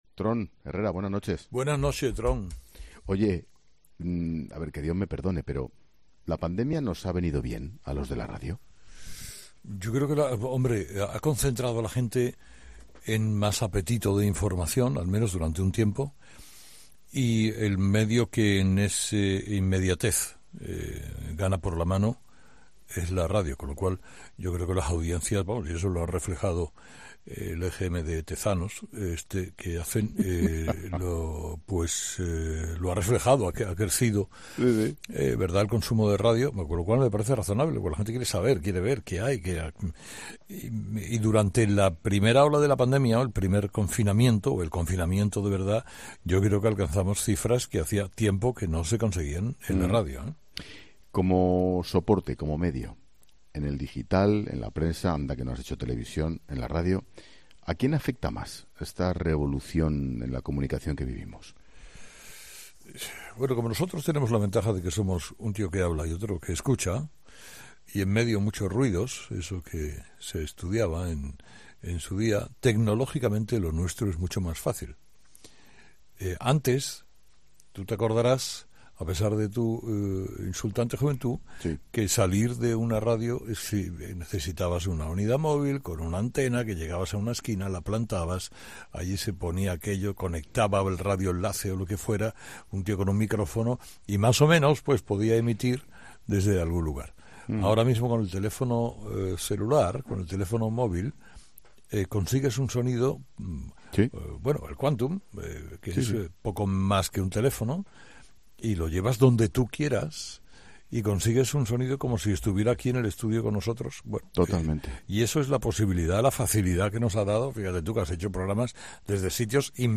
Dos de los comunicadores radiofónicos más importantes de España han pasado por los micrófonos de 'La Linterna' para celebrar este día